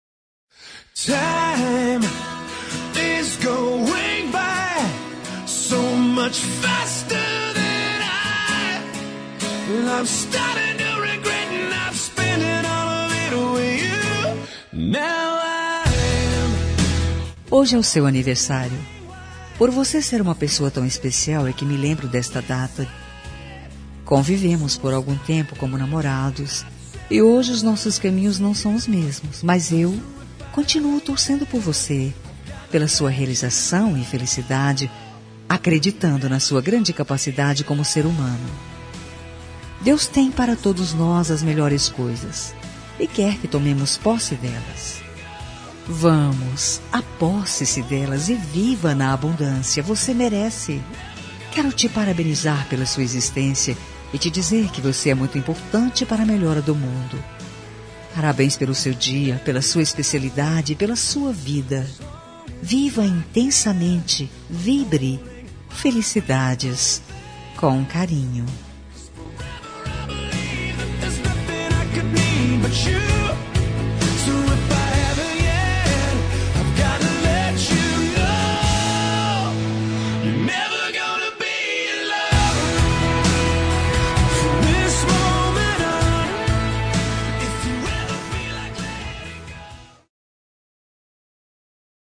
Telemensagem de Aniversário de Ex. – Voz Feminina – Cód: 1358